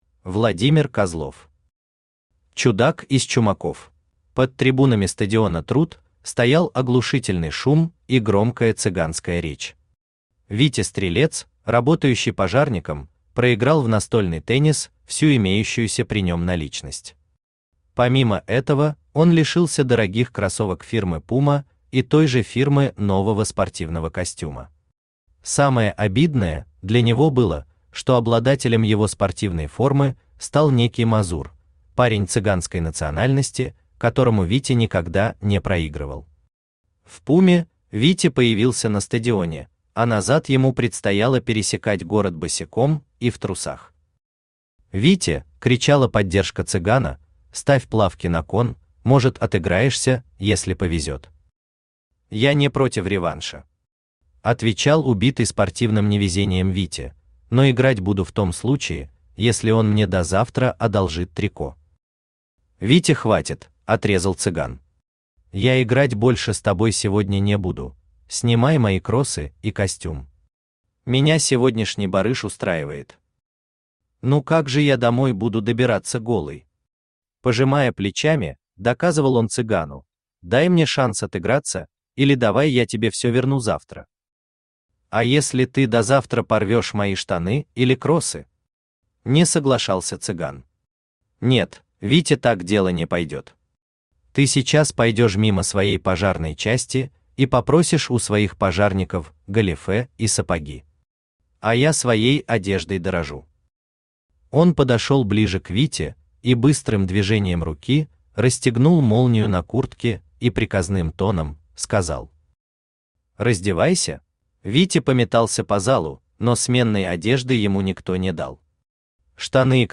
Аудиокнига Чудак из Чумаков | Библиотека аудиокниг
Aудиокнига Чудак из Чумаков Автор Владимир Алексеевич Козлов Читает аудиокнигу Авточтец ЛитРес.